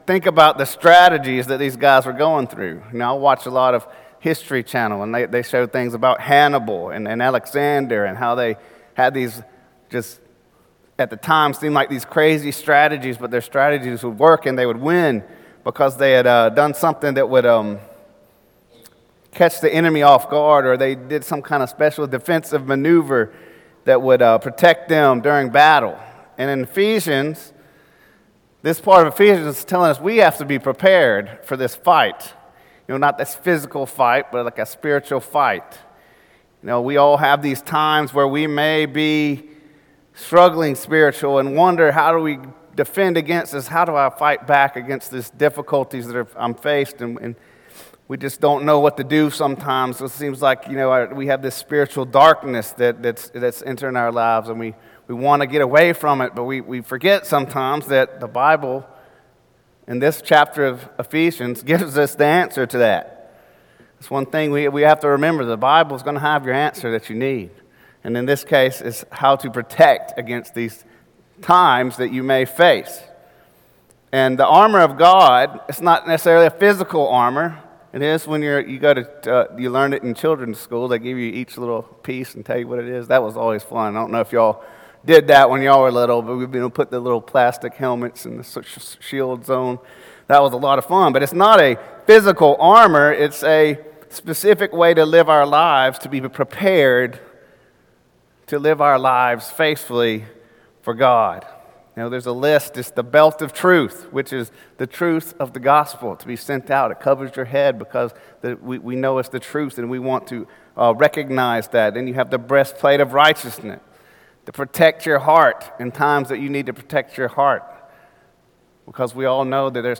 Sermons - Cokesbury Church